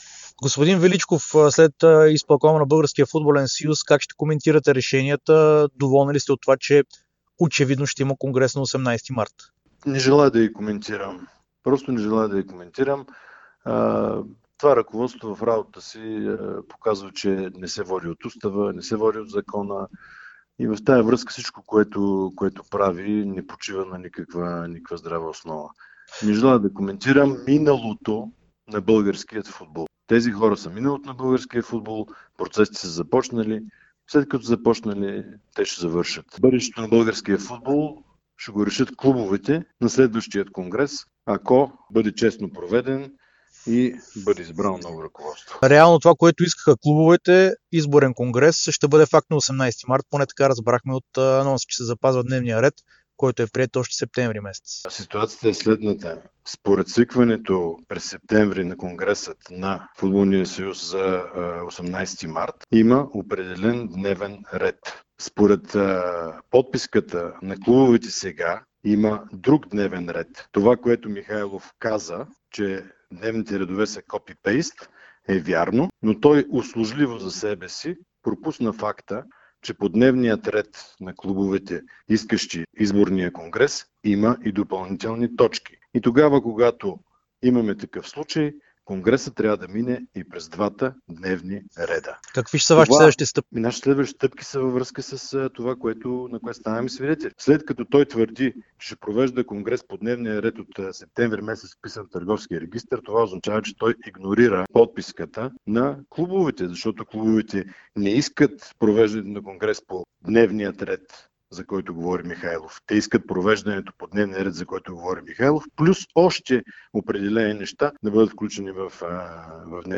говори пред Дарик и dsport след Изпълкома на БФС.